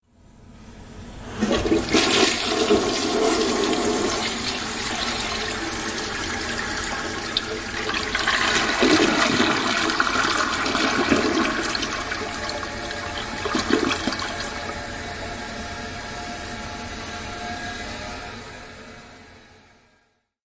浴室里的声音 " 档位马桶的冲水声
描述：公共浴室厕所冲洗。用Zoom H2n记录。
Tag: 公众 给排水 冲洗 冲洗 堵转 厕所 浴室 厕所 洗手间